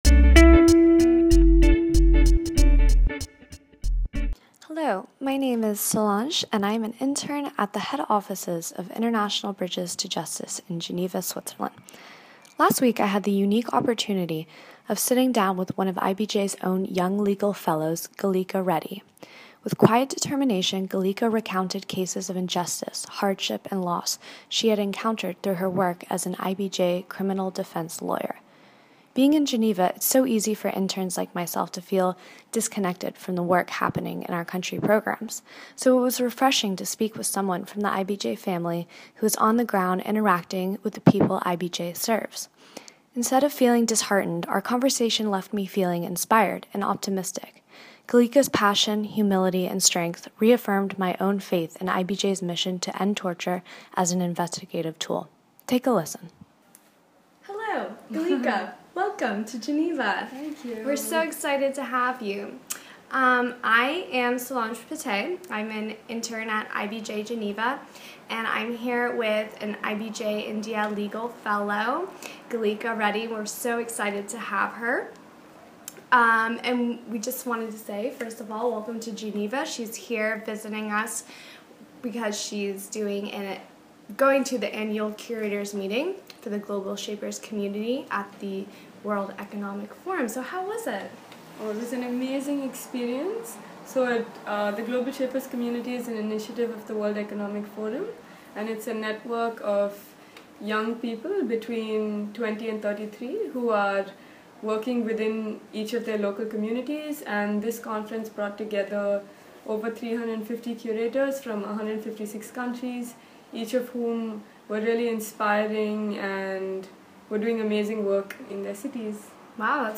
Podcast Interview